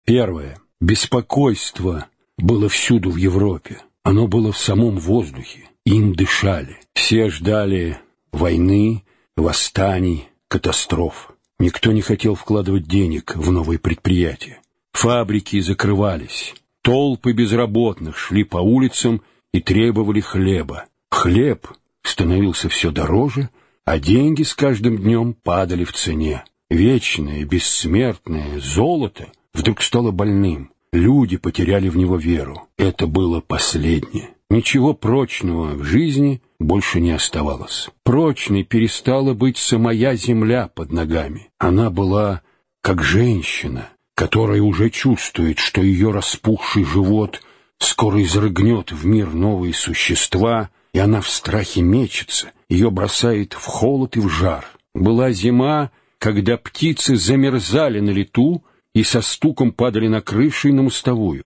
Аудиокнига Бич Божий | Библиотека аудиокниг